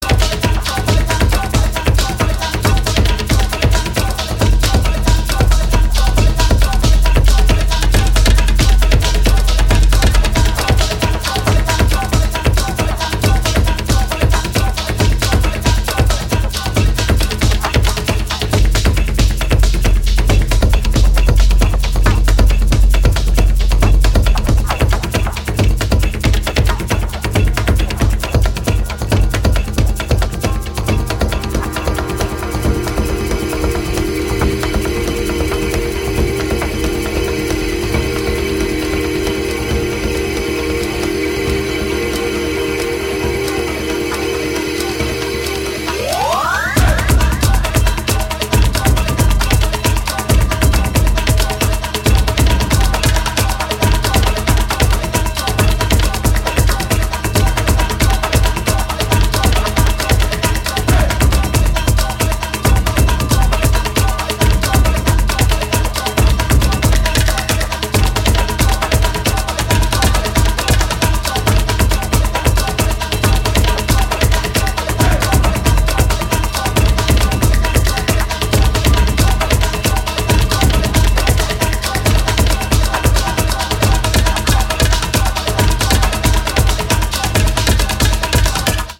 ピークタイム路線の強力な内容ですね。
激しいトライバル・グルーヴでフロアの空気を変えるおすすめ曲